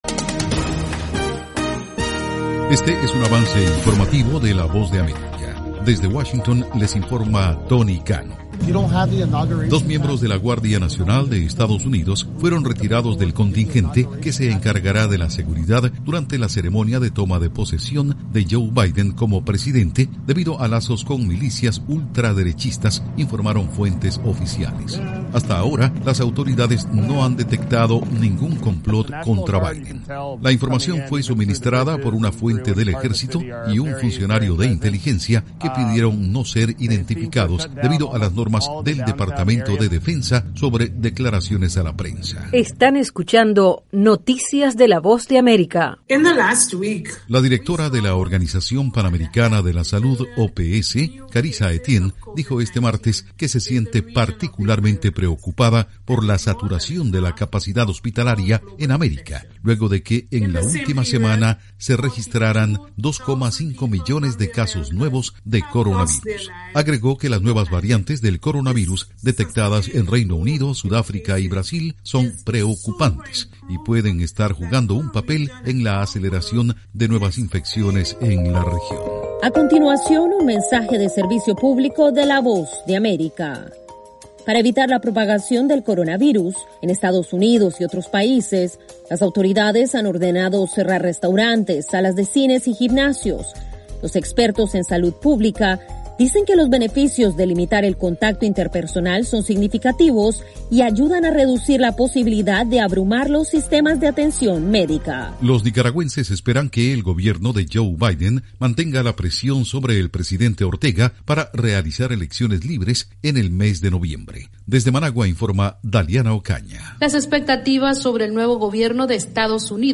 Cápsula informativa de tres minutos con el acontecer noticioso de Estados Unidos y el mundo